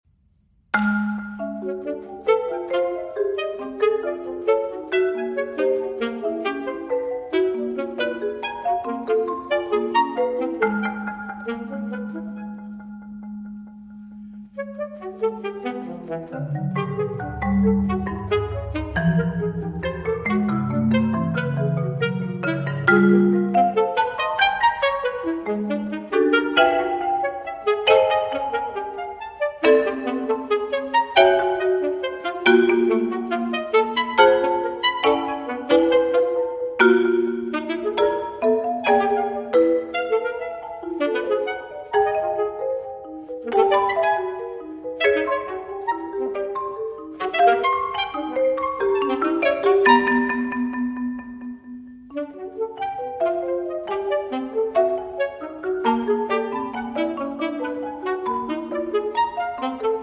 saxophone
marimba